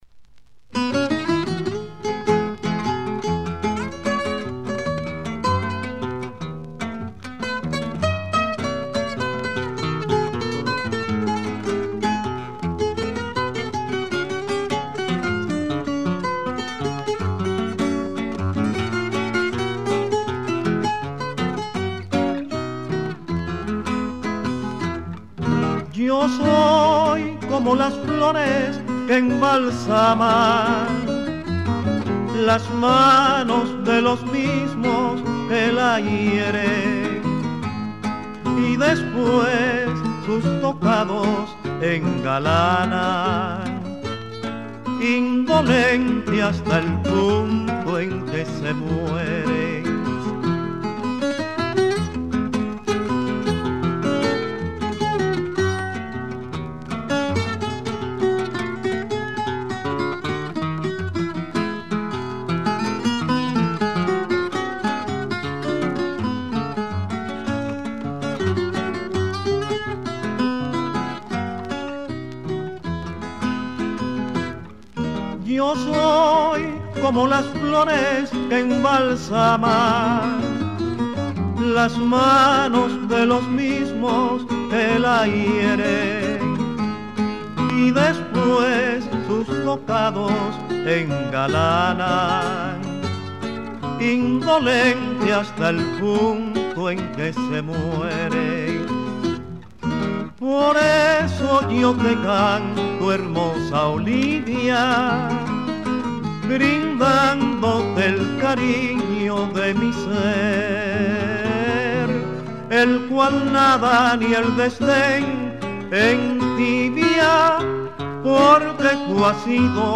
キューバのダンソンの男性ボーカル
本作品では、TOROVA トローバ　、SON ソン　、BOLERO ボレロを唄う